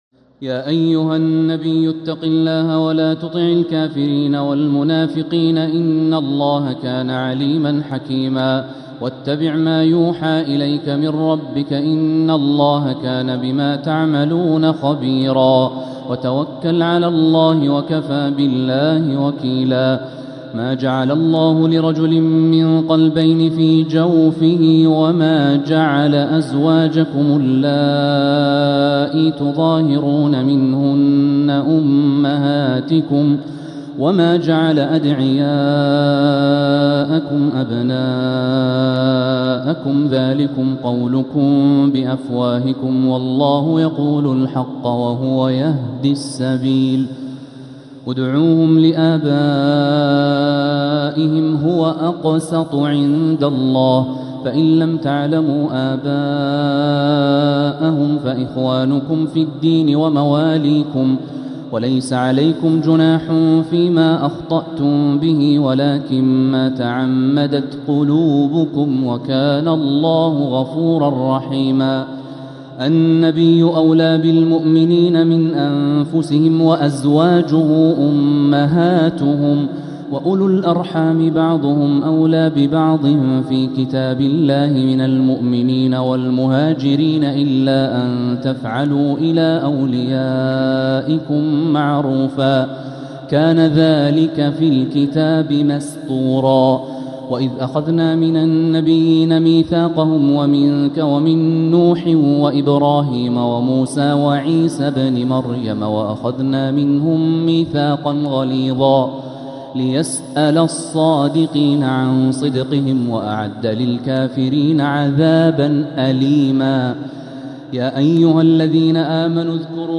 سورة الأحزاب | مصحف تراويح الحرم المكي عام 1446هـ > مصحف تراويح الحرم المكي عام 1446هـ > المصحف - تلاوات الحرمين